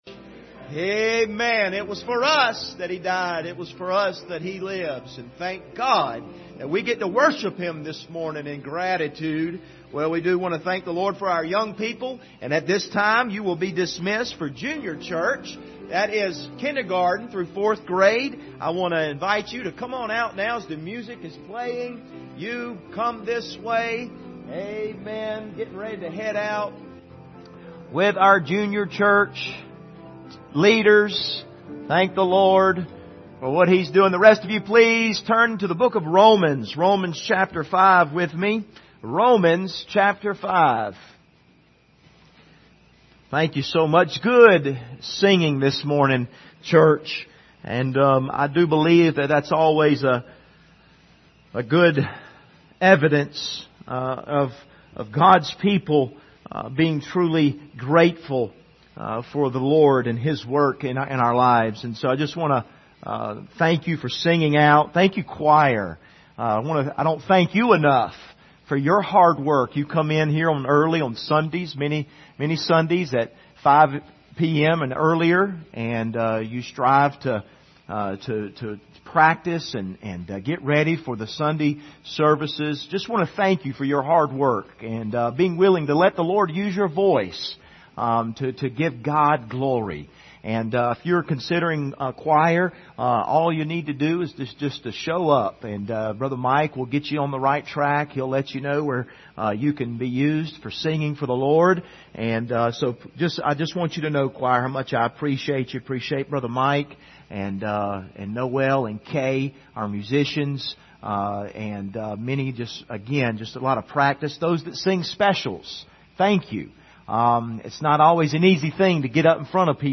The Just Shall Live By Faith Passage: Romans 5:1-6 Service Type: Sunday Morning « Why Are We Doing This?